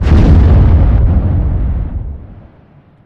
Depth Charge Short
Large depth charge short. WWII explosions.